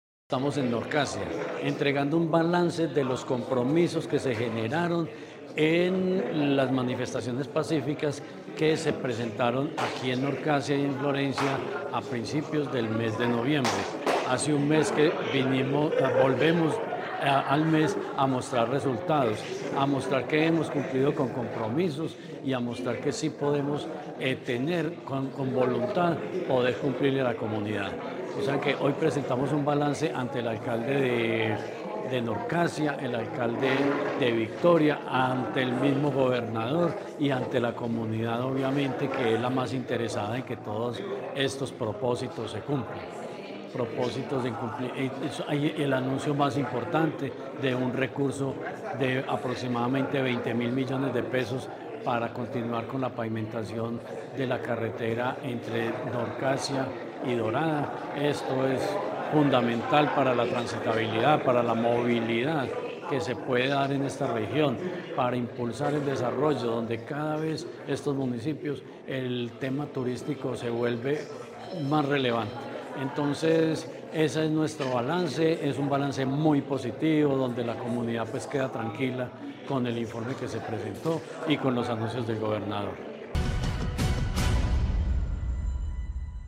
Jorge Ricardo Gutiérrez, secretario de Infraestructura de Caldas